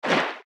Sfx_creature_trivalve_swim_fast_04.ogg